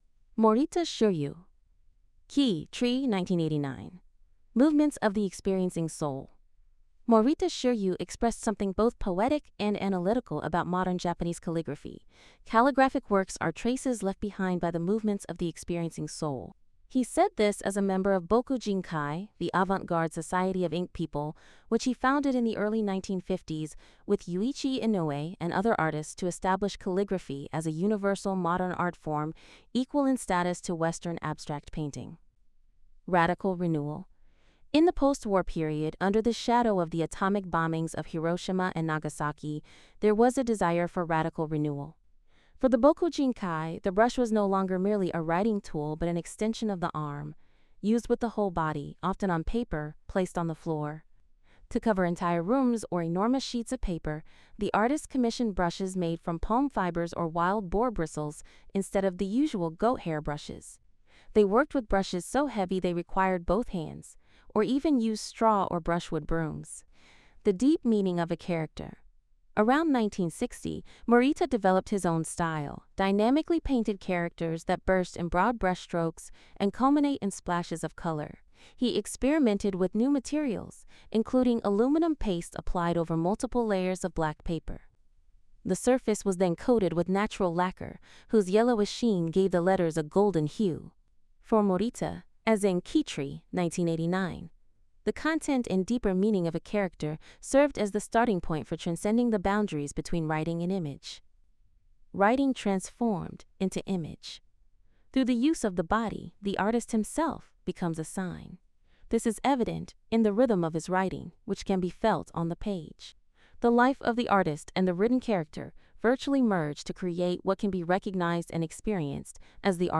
Note: The audio transcription is voiced by an AI.